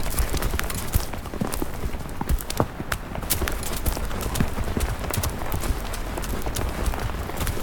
Sfx_creature_snowstalker_run_loop_01.ogg